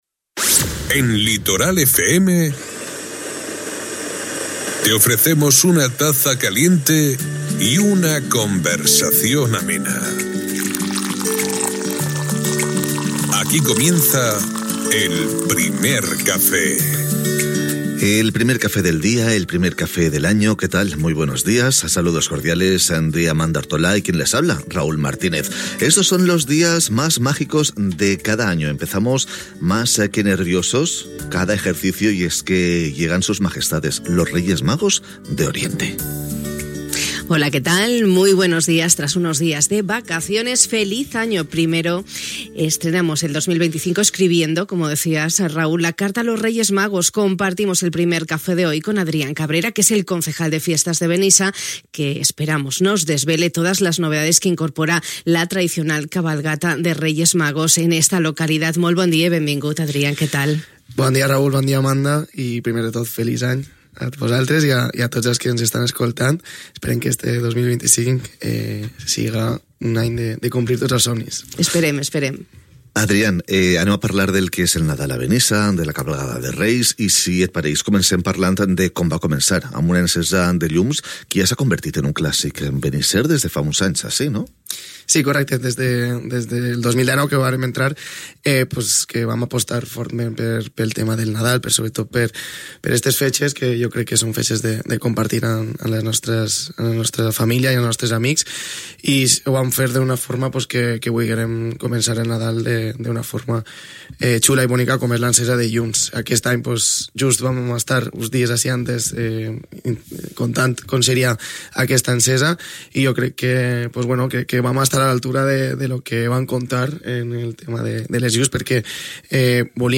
Aquesta matí hem compartit el nostre Primer Cafè amb Adrián Cabrera, regidor de Festes de Benissa, per conèixer tots els detalls sobre les novetats que incorpora la tradicional cavalcada dels Reis Mags.